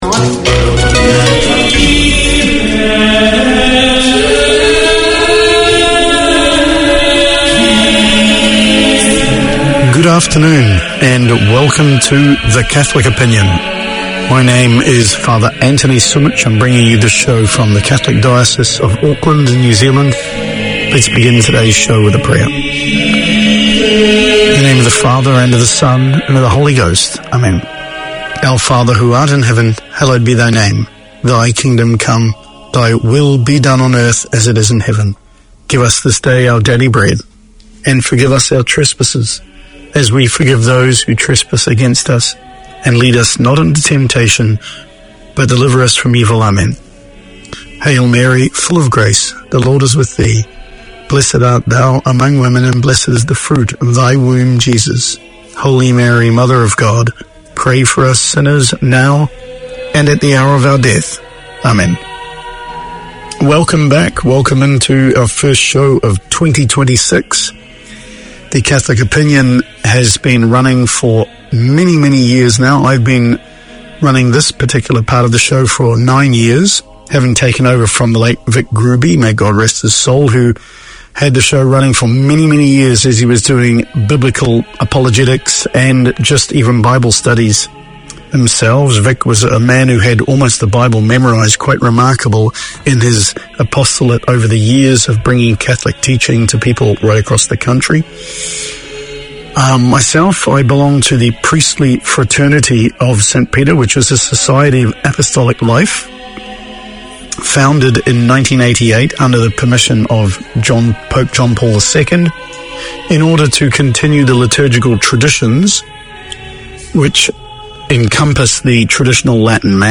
Community Access Radio in your language - available for download five minutes after broadcast.